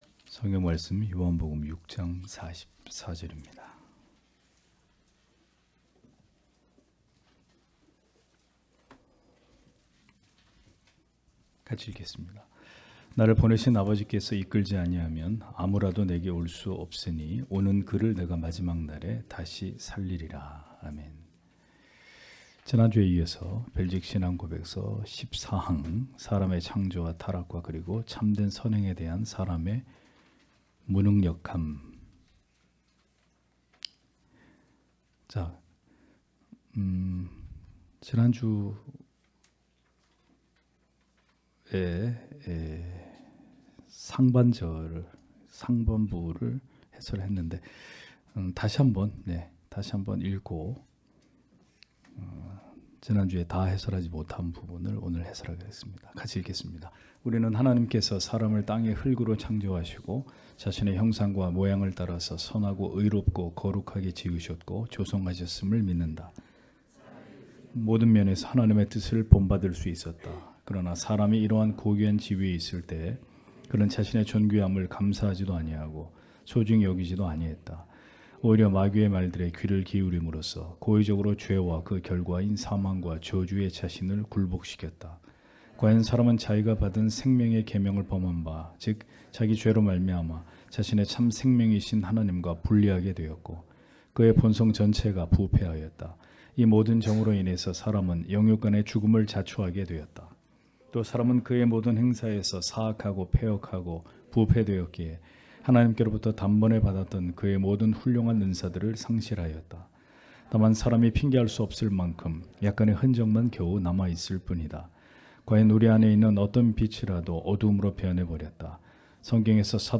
주일오후예배 - [벨직 신앙고백서 해설 15] 제14항 사람의 창조와 타락과 그리고 참된 선행에 대한 사람의 무능력함 (롬 5장12절)
* 설교 파일을 다운 받으시려면 아래 설교 제목을 클릭해서 다운 받으시면 됩니다.